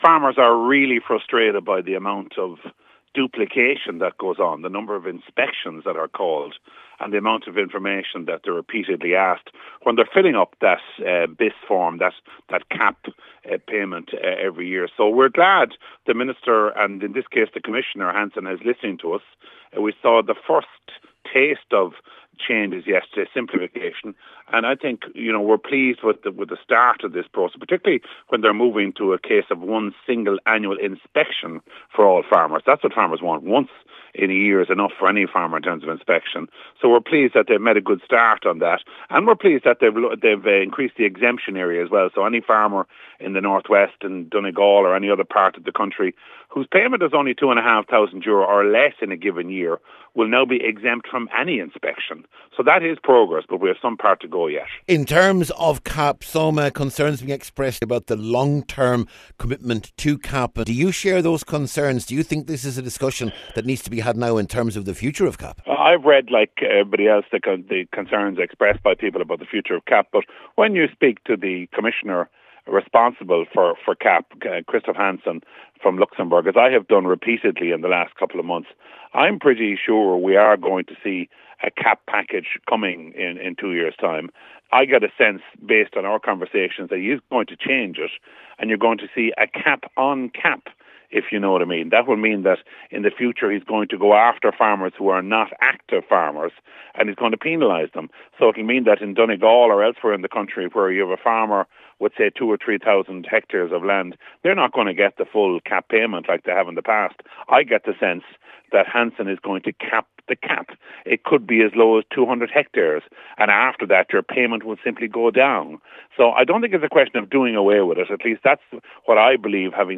He began by outlining the importance of the inspection changes…..……